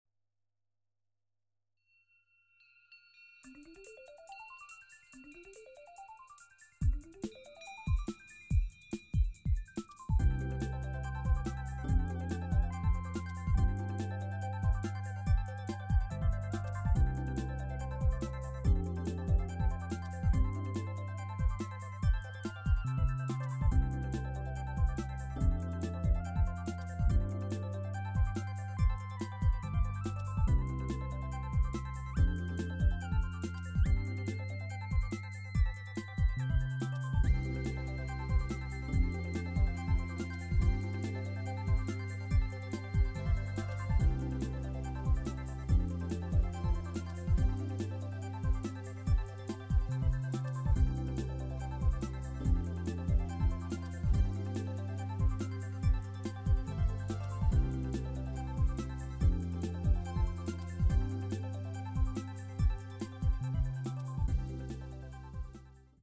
「現代風マスタリング」なので、20Hz～40Hzが既に入っています。
・サブウーファーは80Hz、-12db/octでLPF
・・・-12db/octって、意外と高域残るのね。